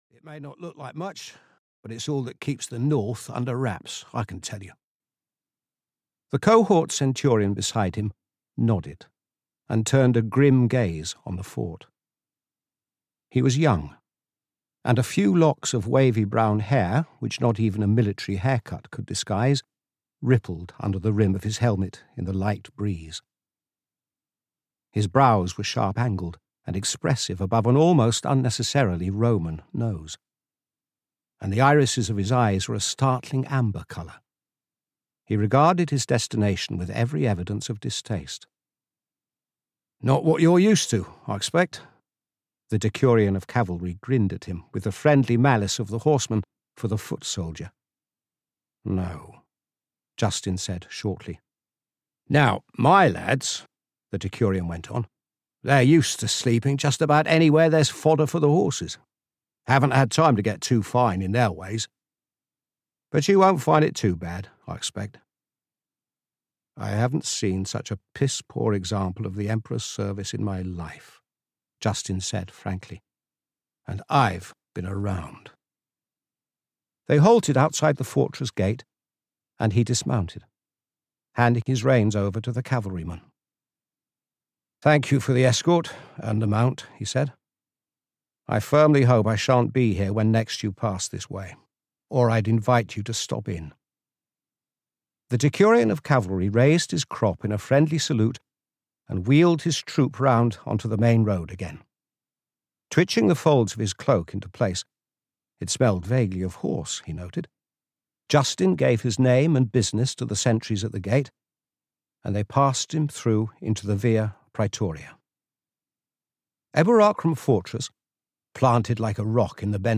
The Legions of the Mist (EN) audiokniha
Ukázka z knihy